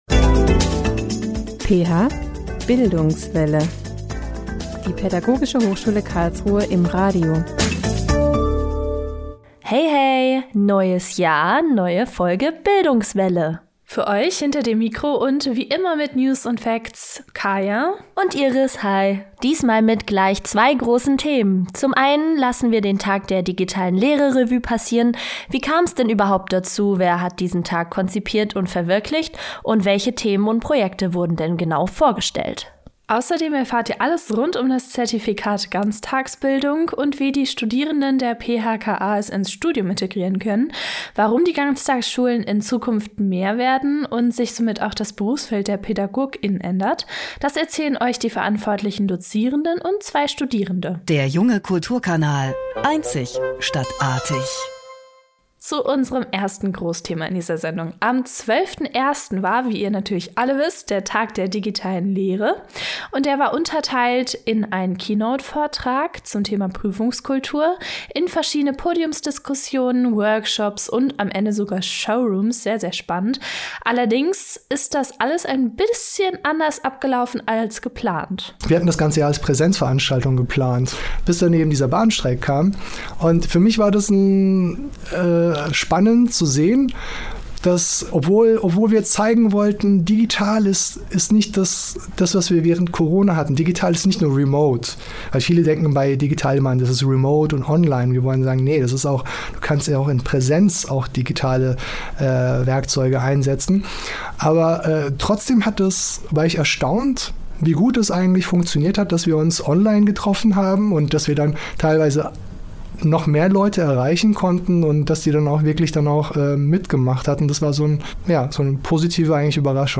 Außerdem erfahrt ihr alles rund um das Zertifikat “Ganztagsbildung” und wie die Studierenden der PHKA dieses ins Studium integrieren können. Warum die Ganztagsschulen in Zukunft an Bedeutung gewinnen und sich somit auch das Berufsfeld der Pädagog*Innen ändert, erzählen euch die verantwortlichen Dozierenden.